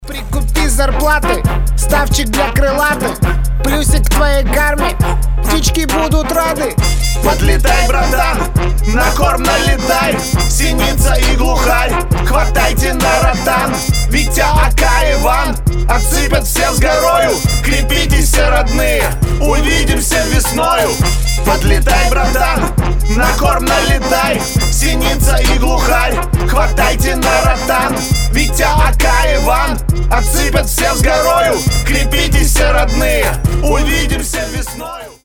• Качество: 320, Stereo
мужской вокал
заводные
пародия